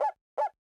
ITA Woof Woof Ab.wav